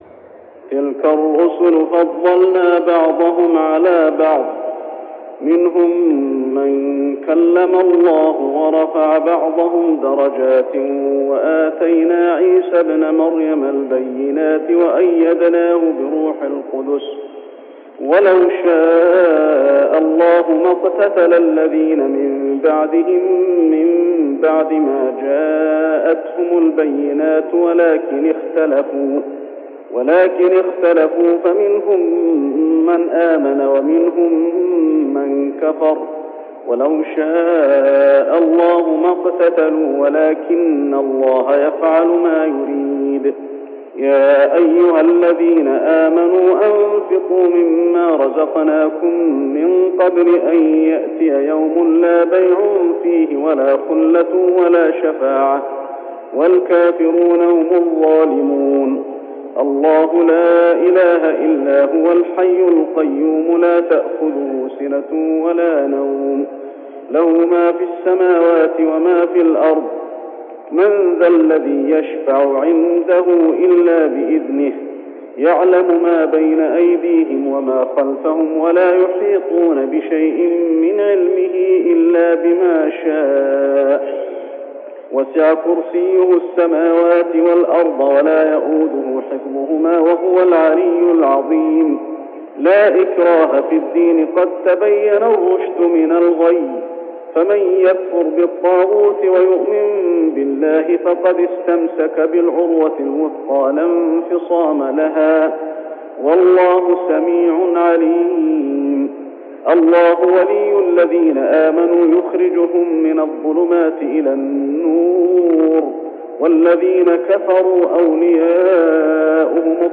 صلاة التراويح ليلة 3-9-1408هـ سورتي البقرة 253-286 و آل عمران 1-92 | Tarawih prayer Surah Al-Baqarah and Al Imran > تراويح الحرم المكي عام 1408 🕋 > التراويح - تلاوات الحرمين